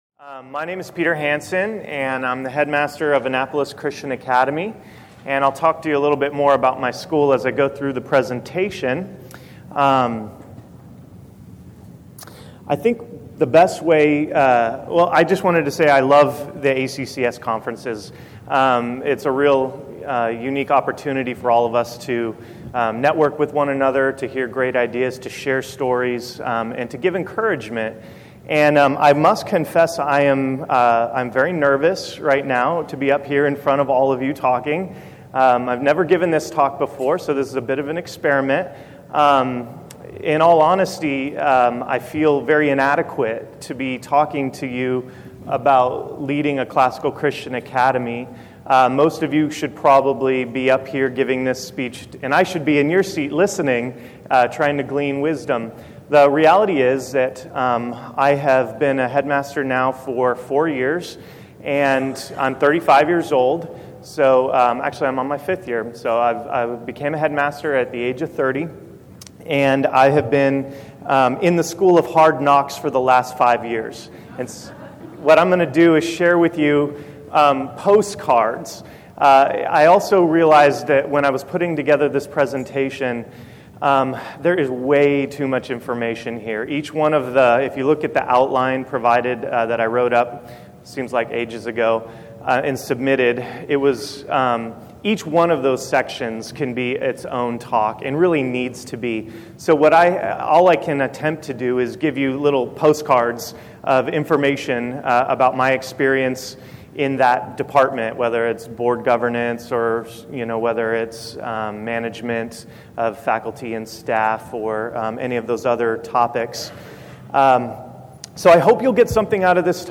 2012 Workshop Talk | 1:07:38 | All Grade Levels, Leadership & Strategic
Speaker Additional Materials The Association of Classical & Christian Schools presents Repairing the Ruins, the ACCS annual conference, copyright ACCS.